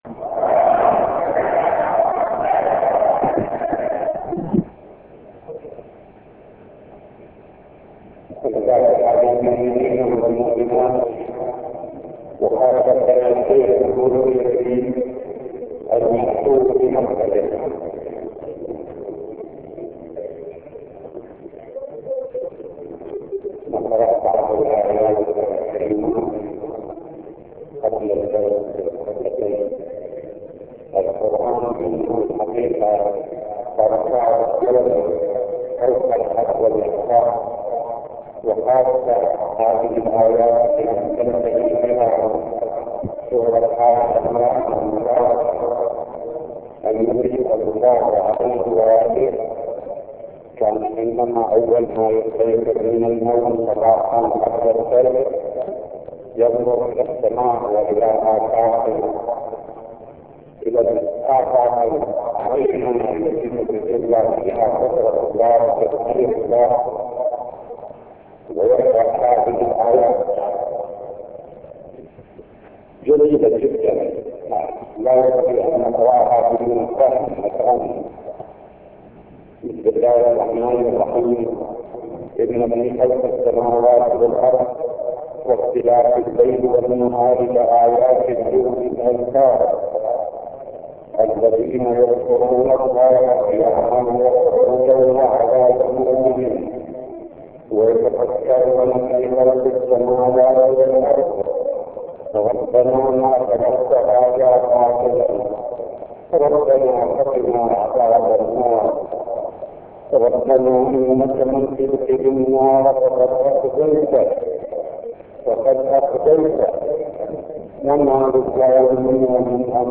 ٢ : خطبتي صلاة الجمعة الثانية في مسجد الكوفة المعظم للاستماع والتحميل
الجمعة الثانية ٢٦/ذي الحجة ١٤١٨هـ التسجيل الصوتي الكامل لخطبتي صلاة الجمعة المباركة الثانية التي القاها سماحة اية الله العظمى السيد الشهيد محمد محمد صادق الصدر في مسجد الكوفة المعظم المعظم .